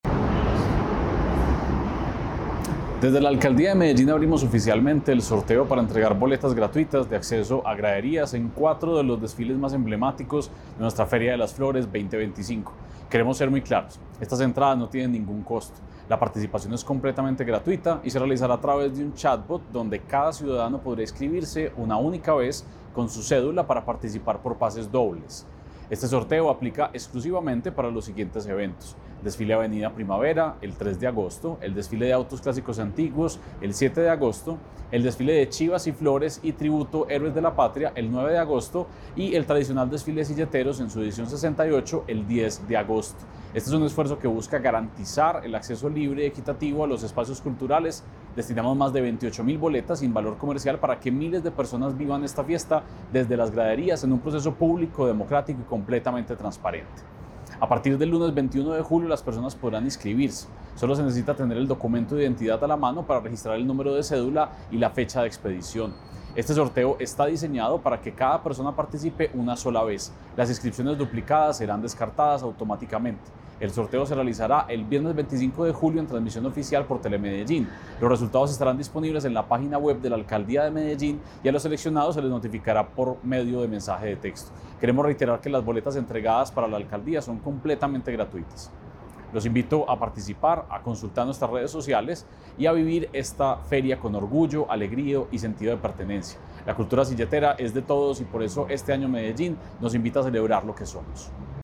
Declaraciones del secretario de Cultura Ciudadana, Santiago Silva Jaramillo.
Declaraciones-del-secretario-de-Cultura-Ciudadana-Santiago-Silva-Jaramillo.-Sorteo-Feria-de-Flores.mp3